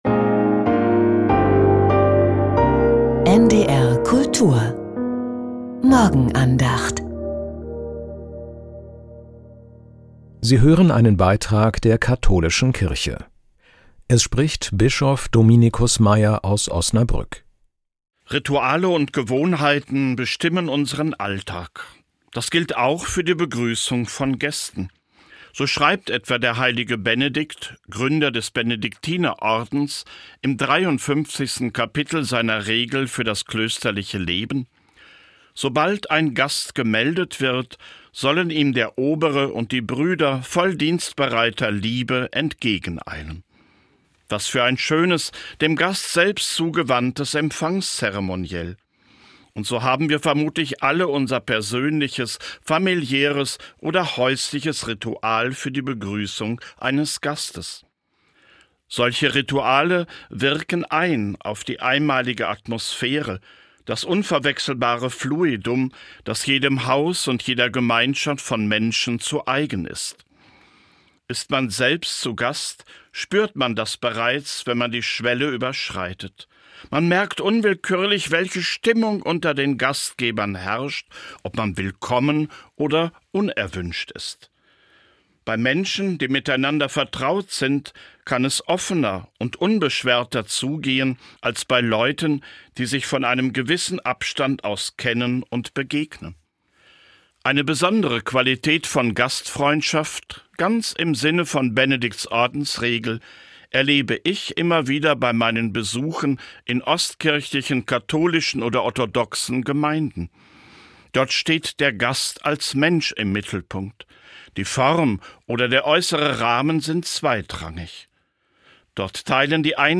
Morgenandacht mit Bischof Dominicus Meier.